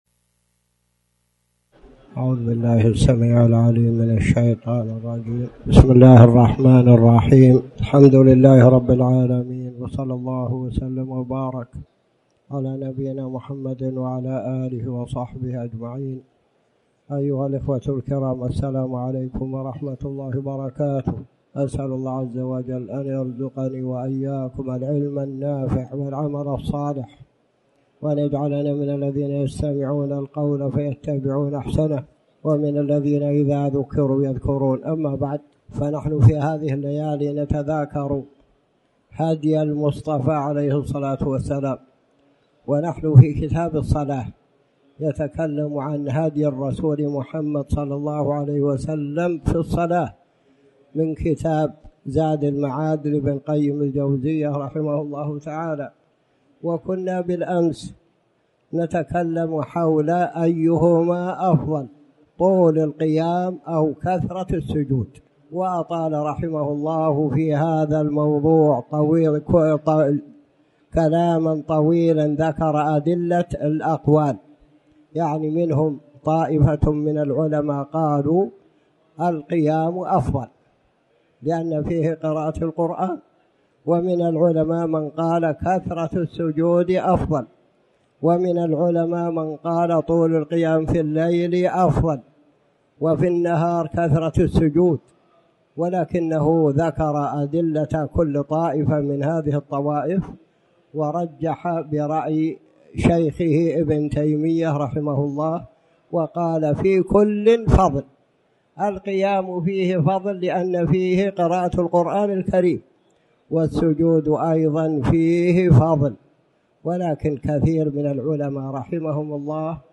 تاريخ النشر ٢٣ رجب ١٤٣٩ هـ المكان: المسجد الحرام الشيخ